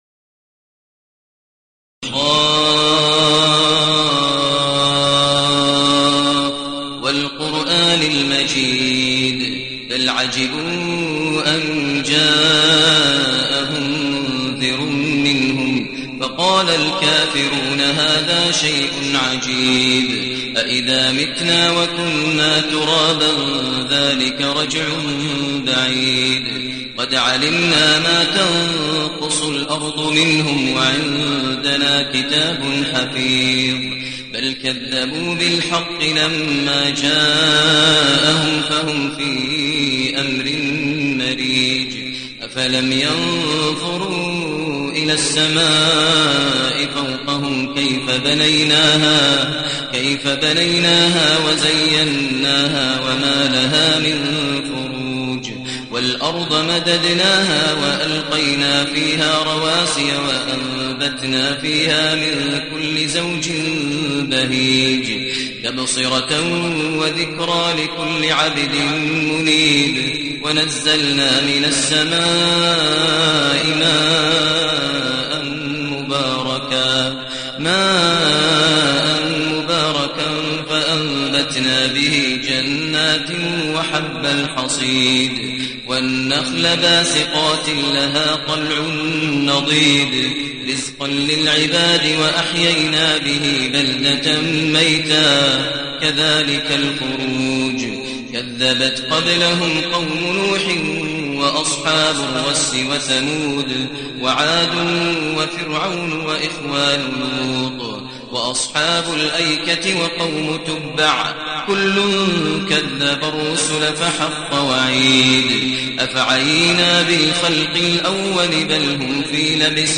المكان: المسجد النبوي الشيخ: فضيلة الشيخ ماهر المعيقلي فضيلة الشيخ ماهر المعيقلي ق